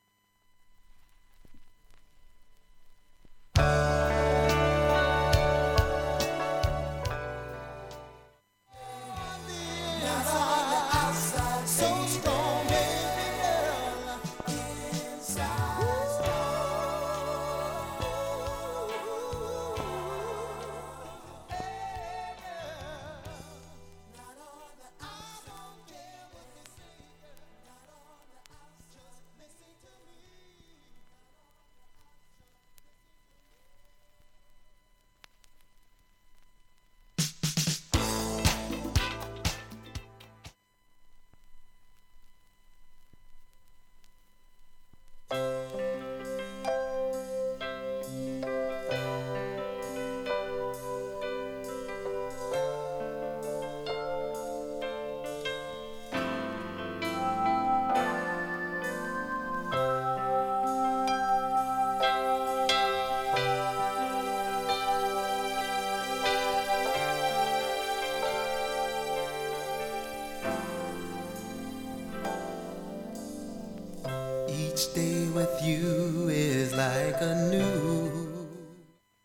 プレス時の小さいバブルがありますが、
盤面きれいでクリアないい音質です。